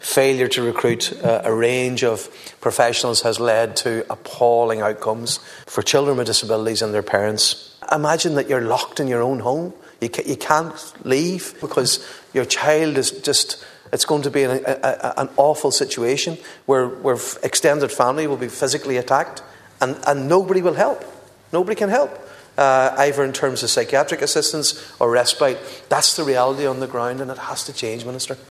Speaking during Dail statements on a National Human Rights Strategy for Disabled People in Ireland over the next five years, Deputy Mac Lochlain highlighted the reality families are facing: